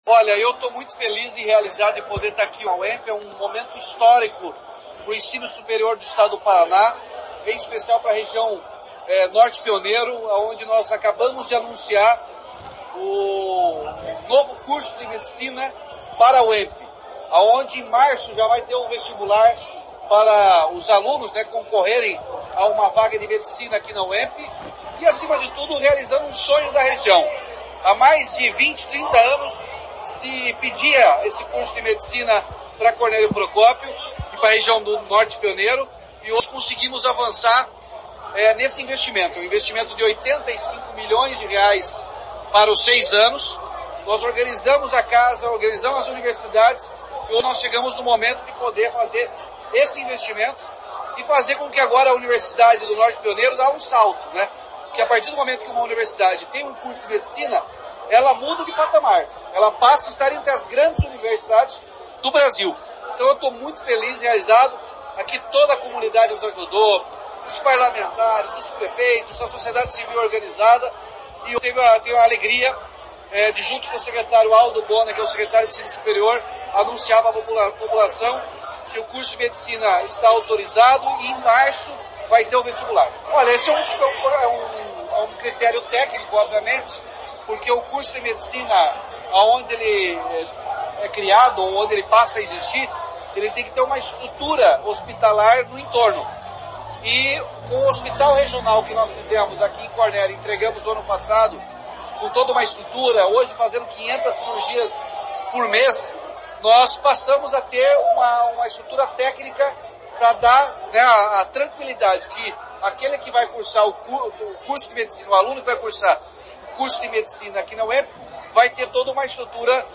Sonora do governador Ratinho Júnior sobre o curso de Medicina na UENP